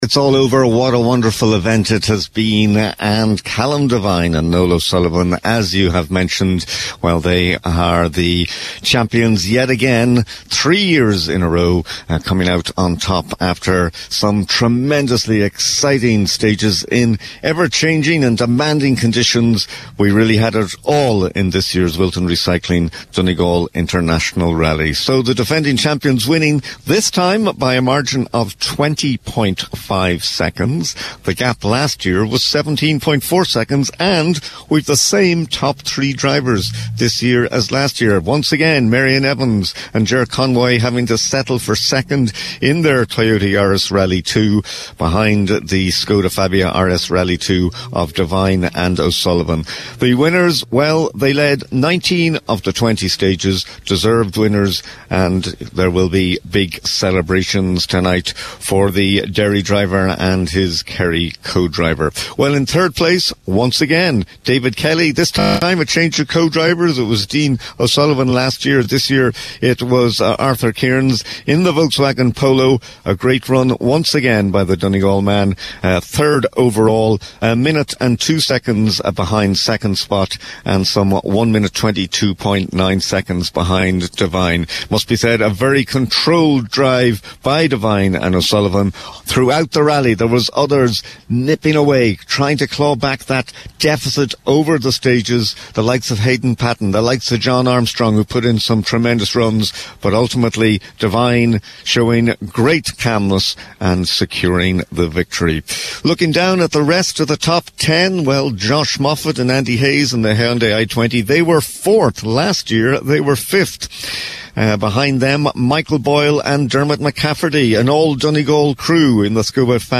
went live on Highland Radio Sunday Sport with a comprehensive wrap of how things finished up in what was an extremely successful event once again…